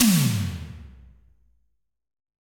Tom_A3.wav